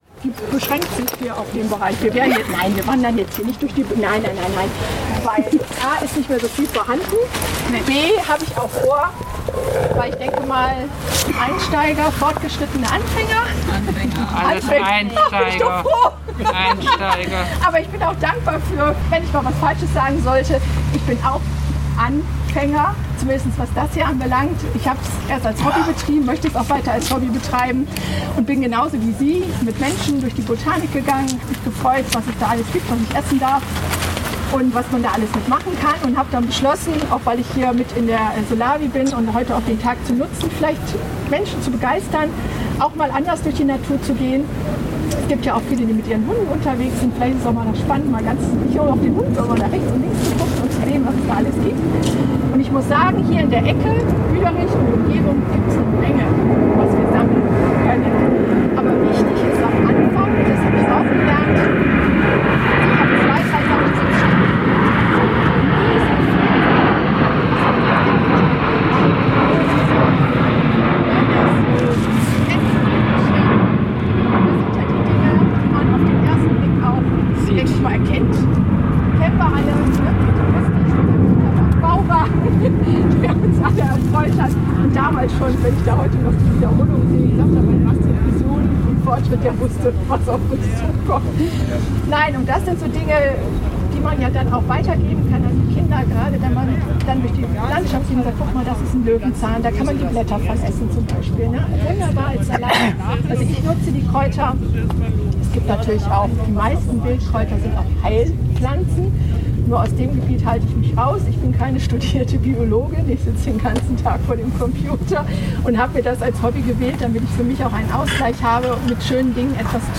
Der demeter-zertifizierte Biohof[1, 2, 3, 4, 5, 6, 7] „Hof am Deich“[8, 9] in Meerbusch-Büderich[10, 11, 12, 13] lud zu einem Hoffest unter dem Motto „Bio trifft Kultur“[14], welches neben verschiedenen Informations- und Verkaufsständen sowie Führungen und Workshops auch ein kulturelles Rahmenprogramm mit Ausstellungen verschiedener lokaler Künstlerinnen sowie musikalischer Unterhaltung bot.